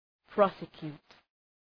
Προφορά
{‘prɒsə,kju:t}
prosecute.mp3